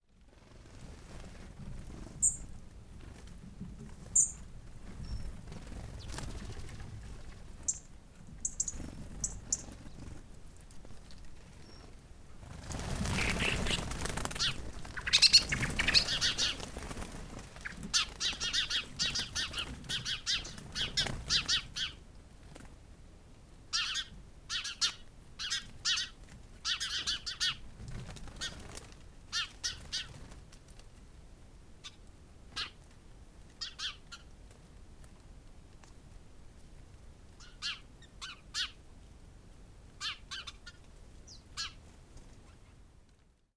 HAUSSPERLING
Warnruf (mit Imitation des Warnrufs einer Amsel)
Der Warnruf bei Bodenfeinden ist als nasaler Ruflaut zu beschreiben. Oftmals ist das ein ‚kew kew‘ oder ein ‚terrettet‘.
Etwas sehr unerwartetes ist, dass der Haussperling sogar den Warnruf von Amseln und Staren nachahmen kann (dies kann man in der obigen Audio sehr gut hören!).
HaussperlingAlarmruf.mp3